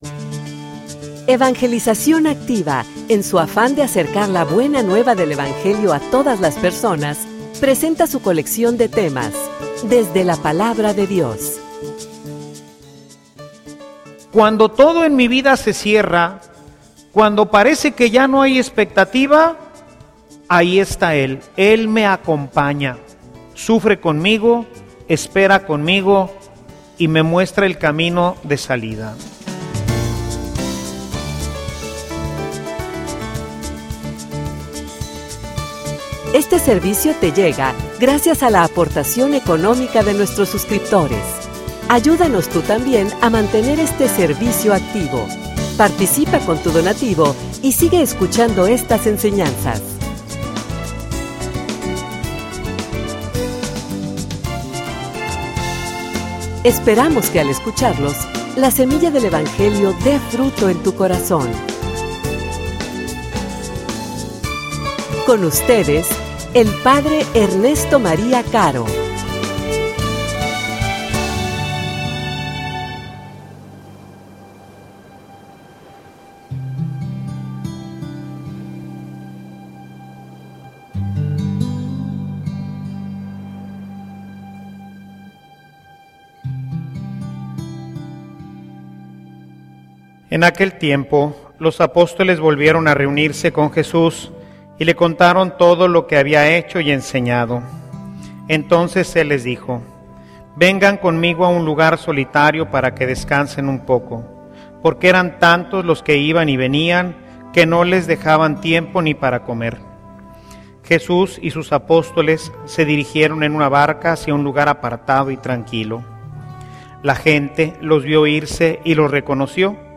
homilia_El_Senor_es_MI_pastor.mp3